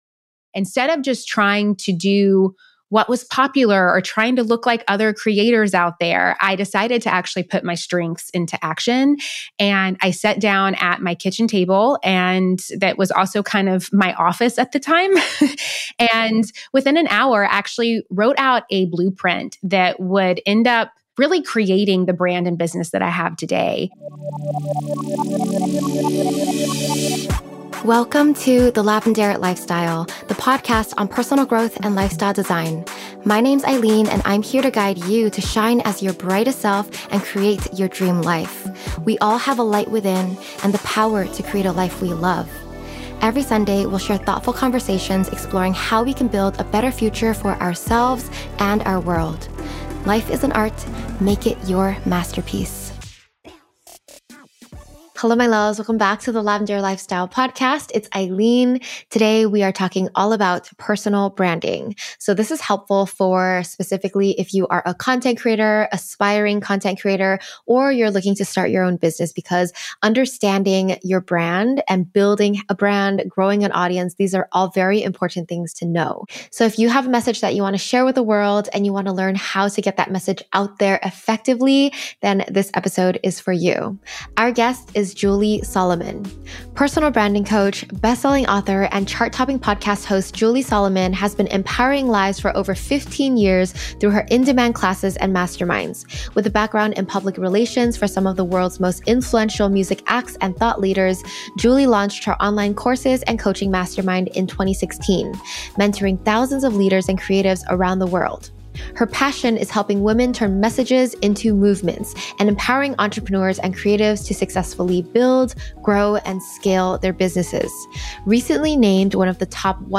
The video version of this interview is available on YouTube: Episode 252